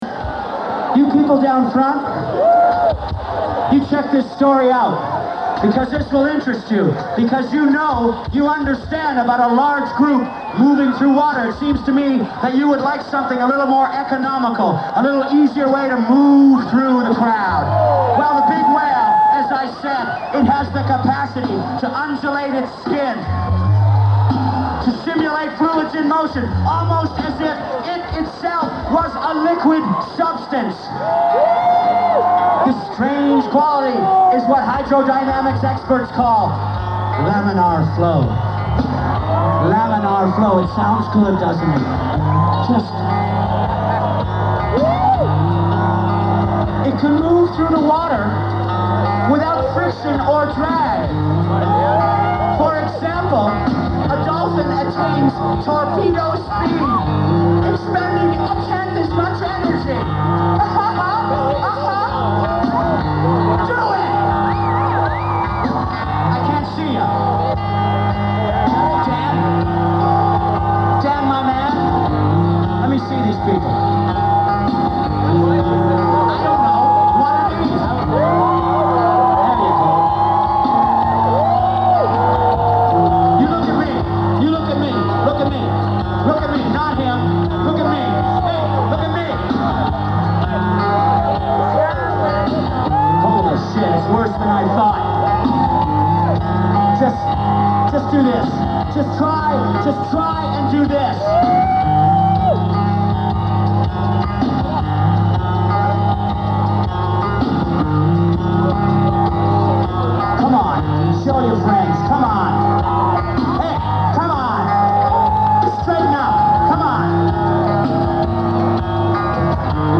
Source: Audience